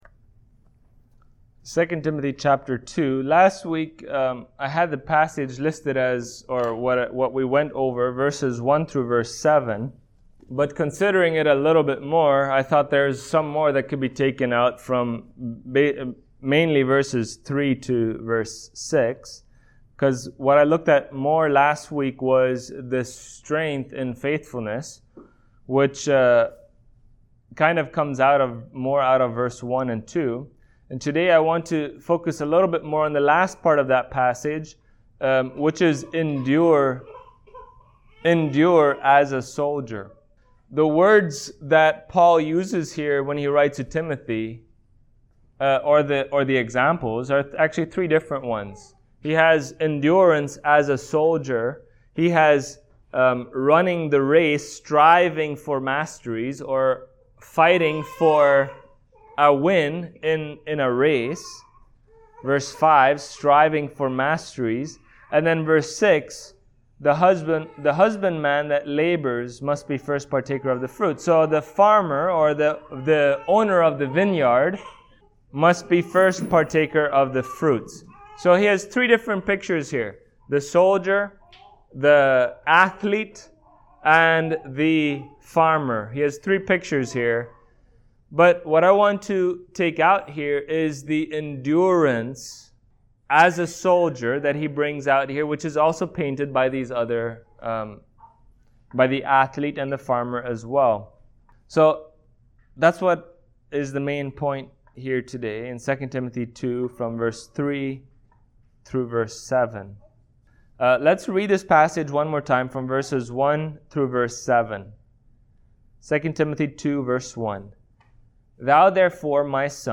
Passage: 2 Timothy 2:3-7 Service Type: Sunday Morning